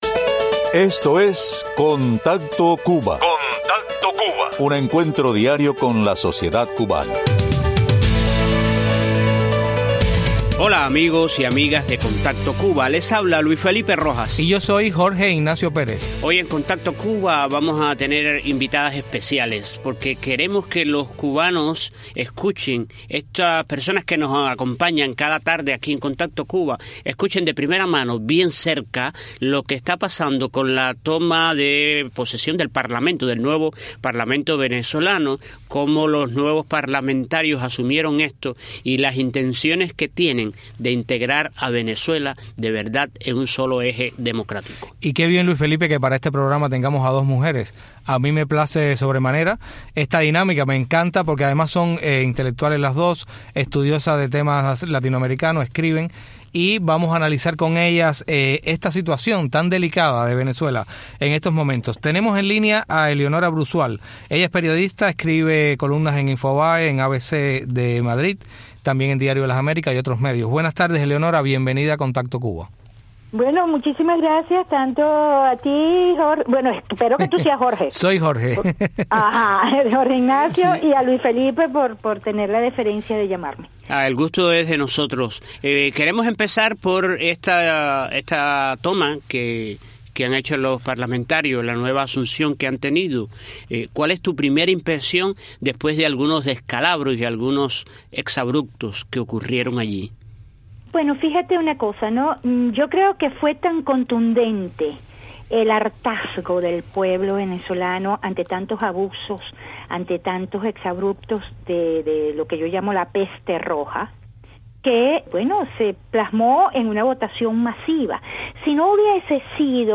Luego de la toma de sus puestos los nuevos parlamentarios venezolanos, con una amplia mayoría de la oposición, la realidad política y social del país podría cambiar. Entrevistamos a 2 analistas de la situación